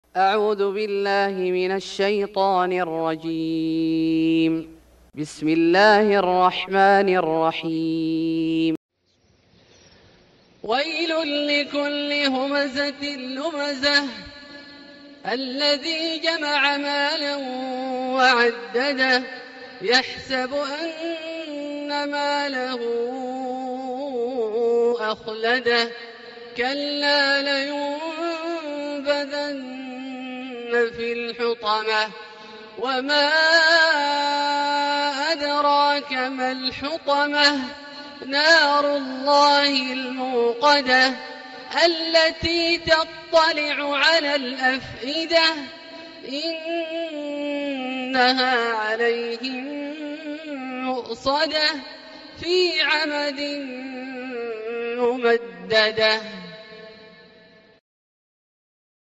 سورة الهمزة Surat Al-Humazah > مصحف الشيخ عبدالله الجهني من الحرم المكي > المصحف - تلاوات الحرمين